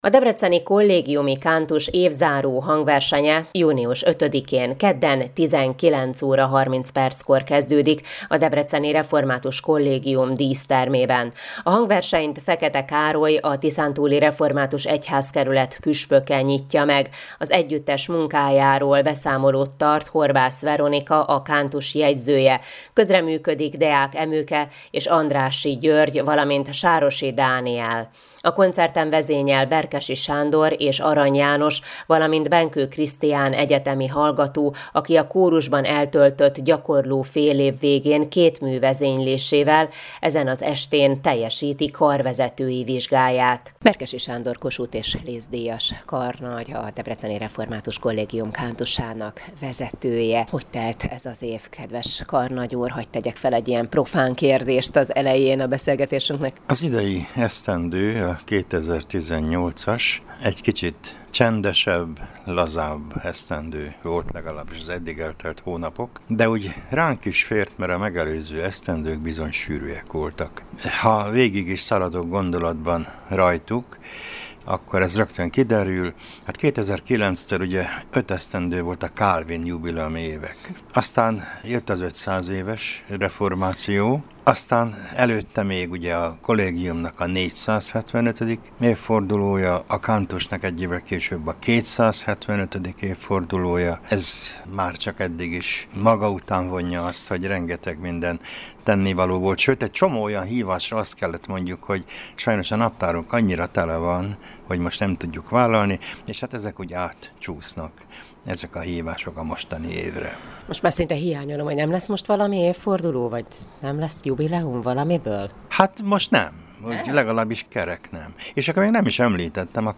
készített interjút az Európa Rádióban